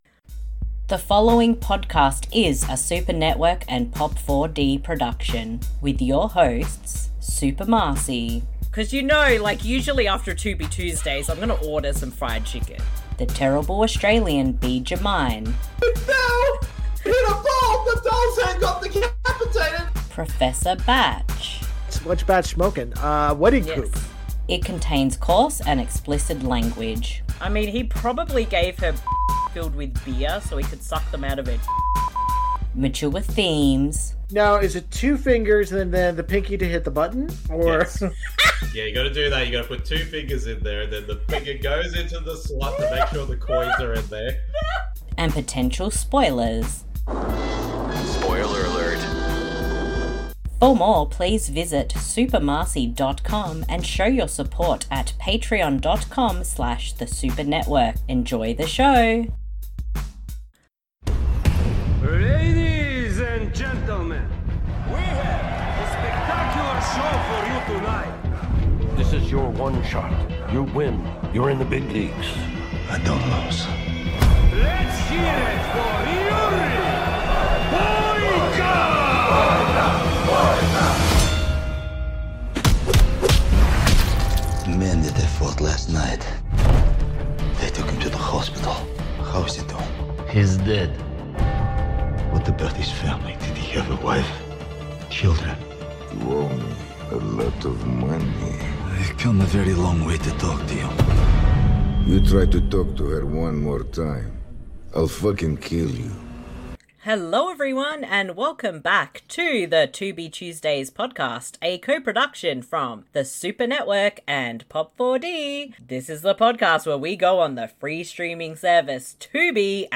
If you have never listened to a commentary before and want to watch the film along with the podcast, here is how it works.
* Like Russian accents? Well we do them … a lot.